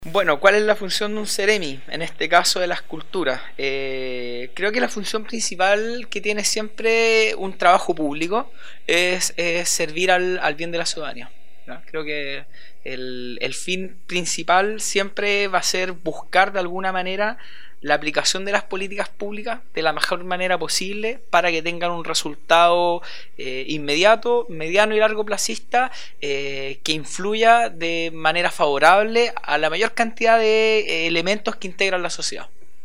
Proyecto “El mundo cívico que todos debemos conocer” entrevista al Seremi de las Culturas y las Artes en La Araucanía
En nuestra quinta entrevista de este proyecto nos dirigimos hasta la Seremi de Las Culturas y el Arte para hablar con Enzo Cortesi Seremi de esta cartera de Gobierno.